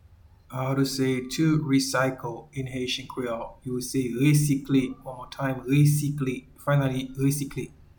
Pronunciation:
to-Recycle-in-Haitian-Creole-Resikle.mp3